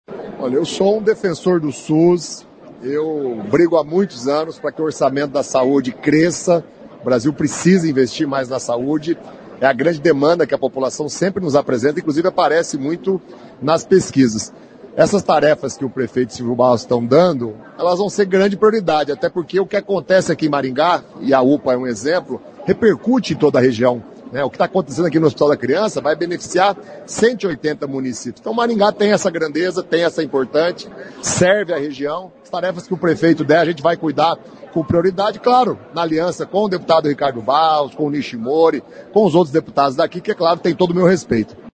O deputado federal Zeca Dirceu comentou o projeto das UPAs para Maringá.